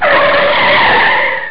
Car braking #2
Car stooping with a long tire skid.